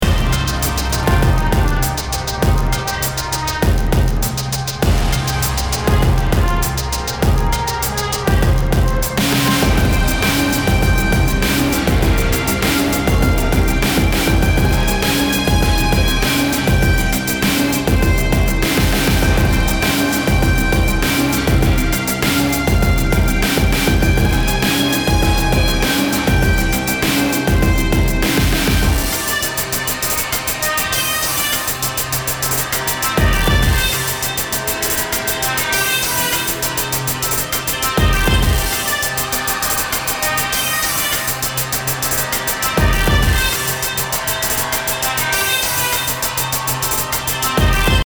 BPM 100